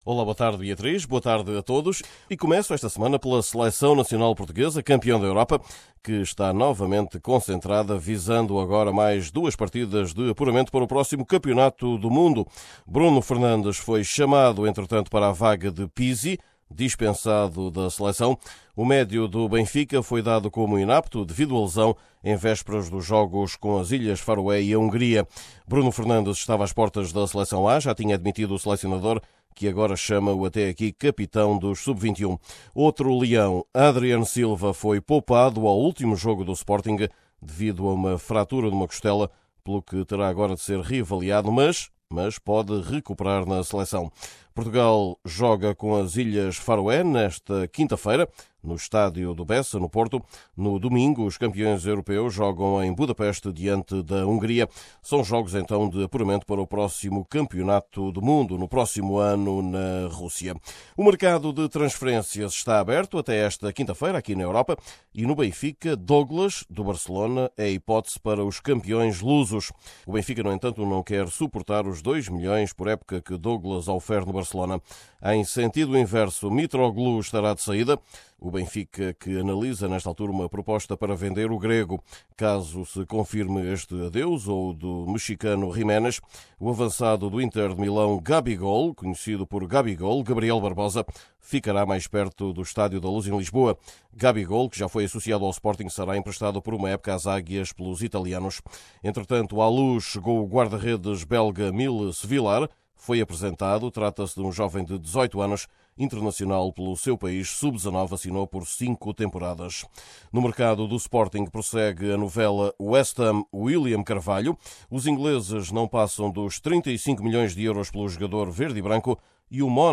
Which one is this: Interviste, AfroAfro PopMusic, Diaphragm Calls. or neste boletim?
neste boletim